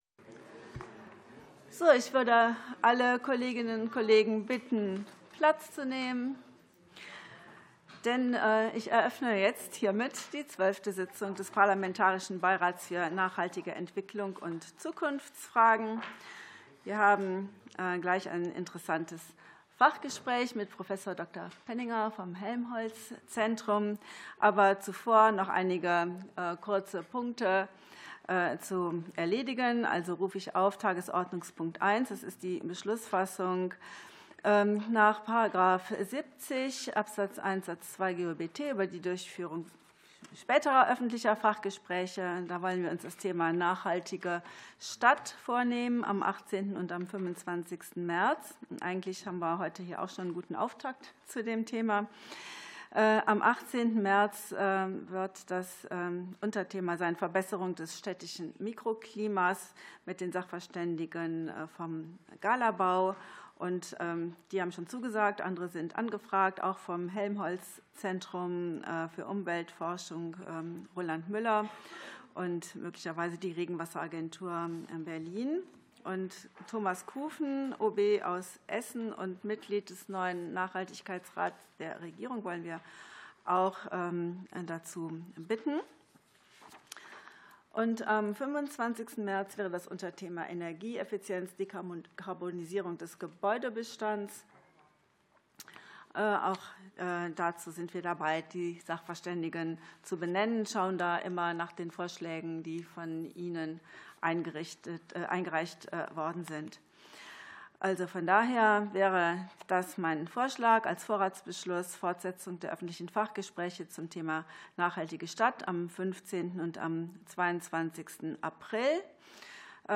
Fachgespräch des Parlamentarischen Beirats für nachhaltige Entwicklung